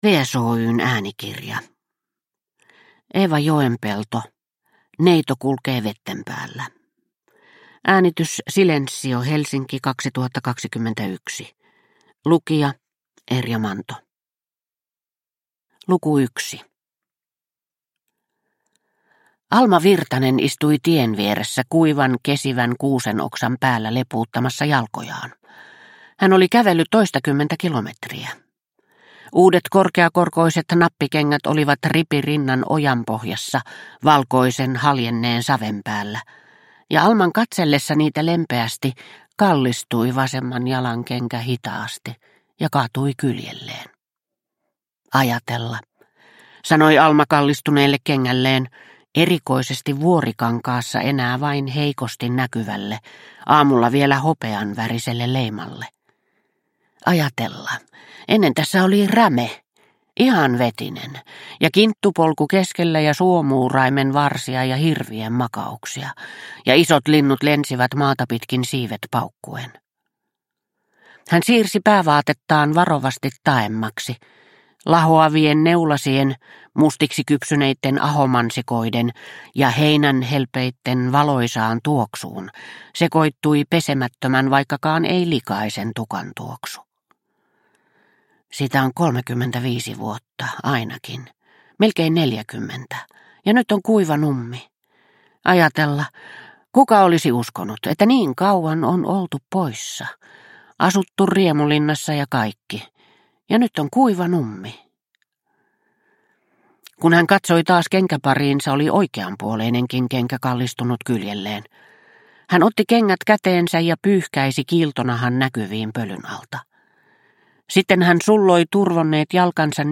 Neito kulkee vetten päällä – Ljudbok – Laddas ner